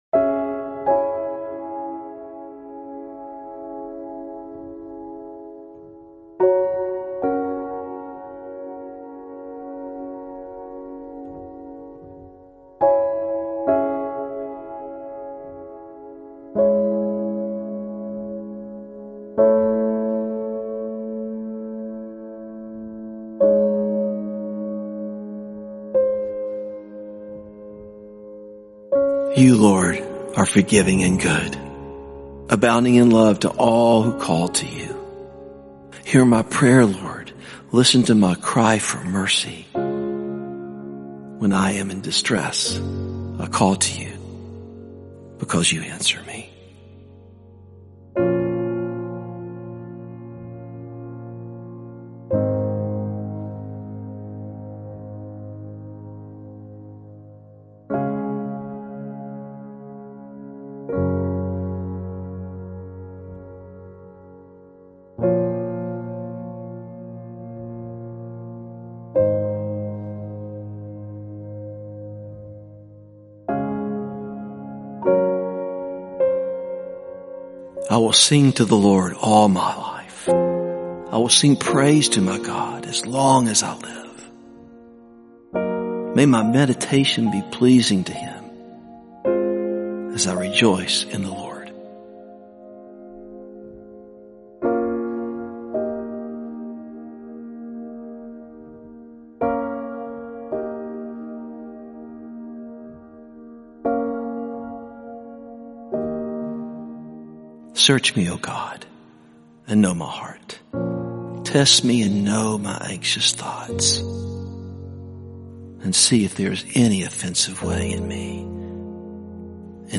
calming, introspective, and soothing instrumental music